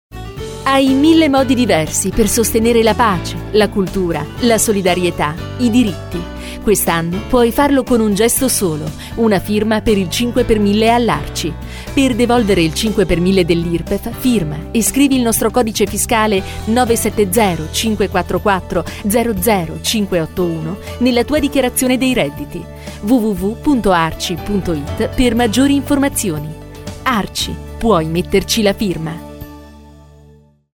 SPOT5x1000.mp3